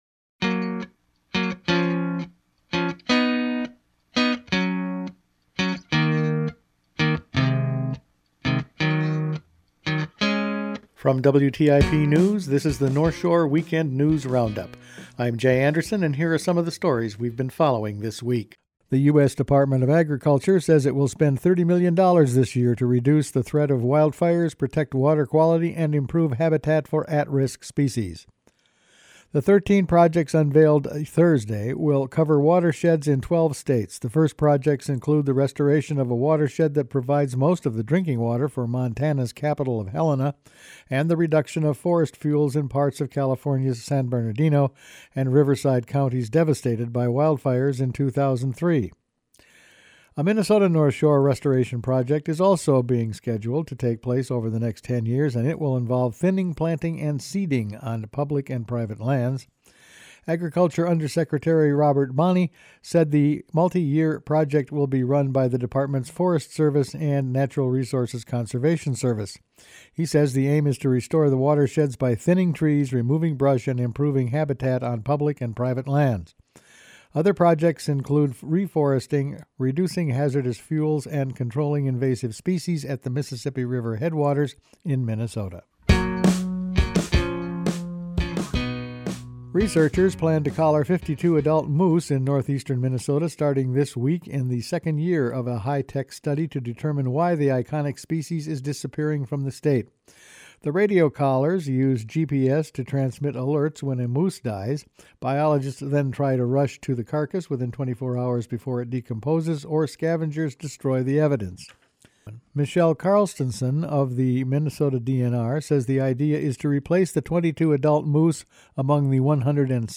Weekend News Roundup for February 8